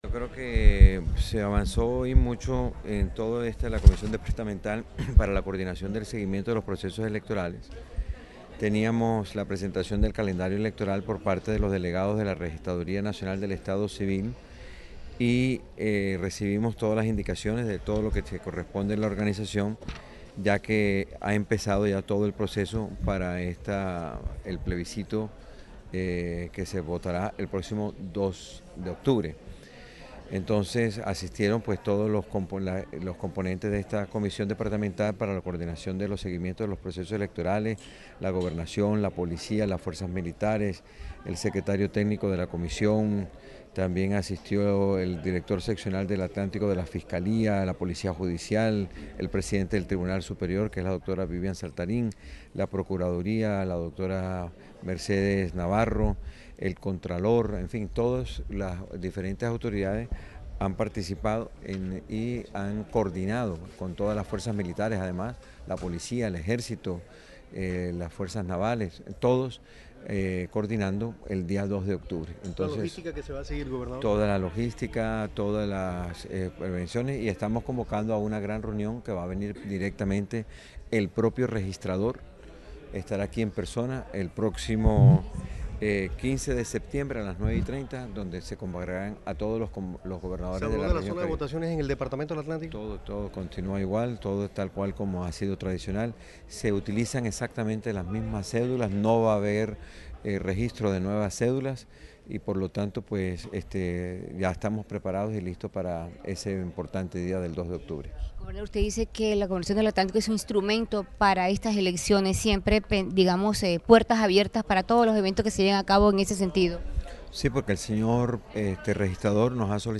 Audio-gobernador-Eduardo-Verano-habla-del-Comité-de-Seguimiento-Electoral.mp3